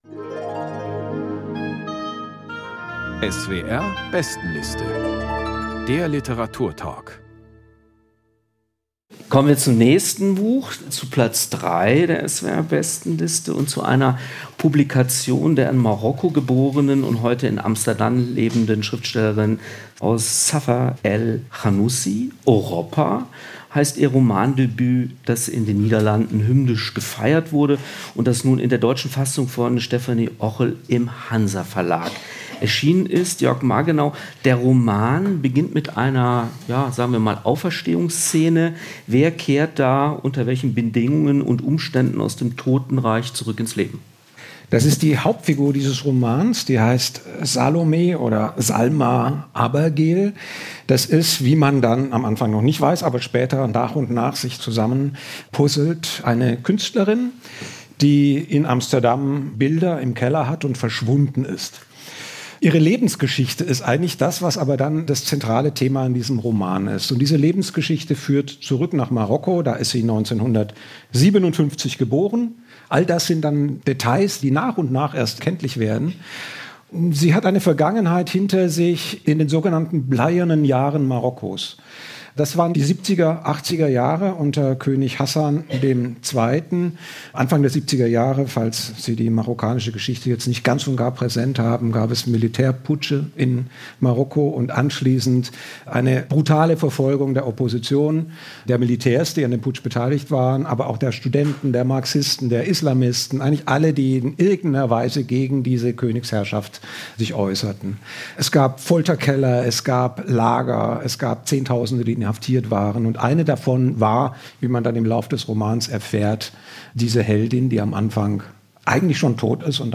Lesung und Diskussion ~ SWR Kultur lesenswert - Literatur Podcast